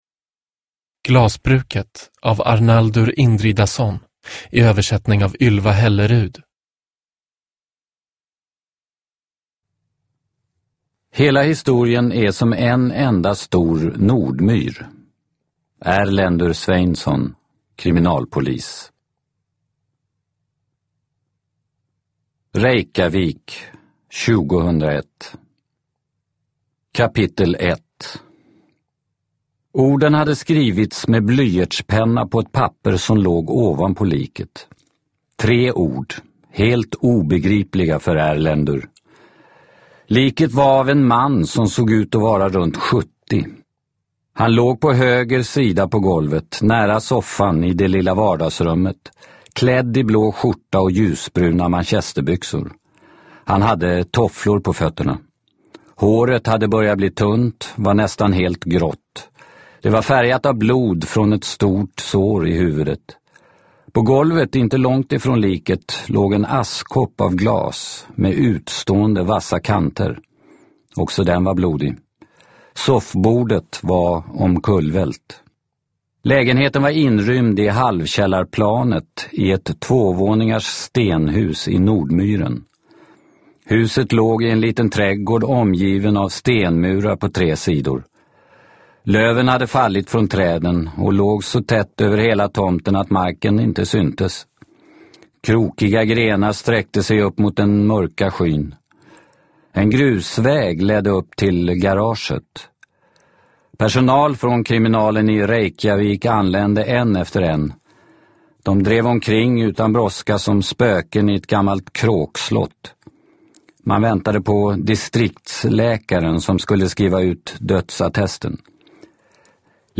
Glasbruket – Ljudbok – Laddas ner